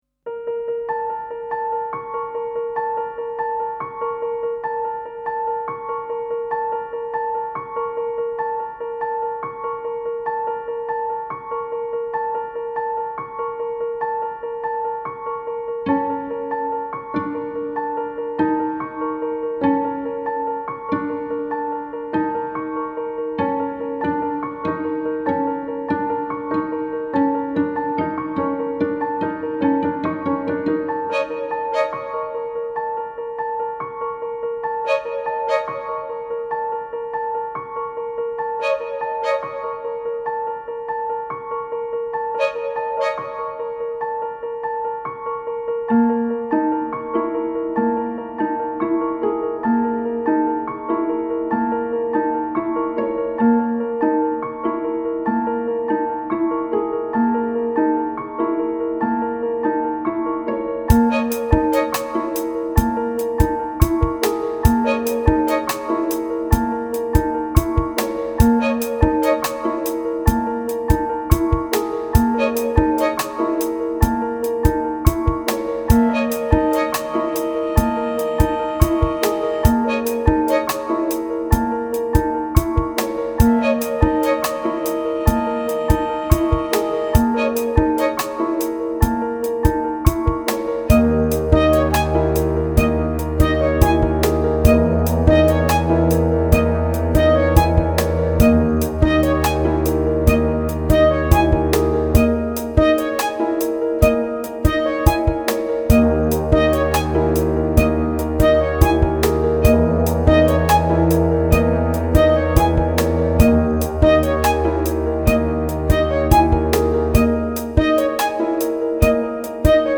I was just playing around with this little tape loop machine, when by chance this noisy but interesting pattern in 9/8 emerged from nowhere.
The pieces in the suite were named after cities in Europe, and that piece sounded very "northern", so I called it Tromsø, although I've never actually been there (You can listen to a computer version of that piece
I'm very happy with that piece, it has a certain "grandeur" and a nice long build-up where the tempo almost imperceptably shifts to double time.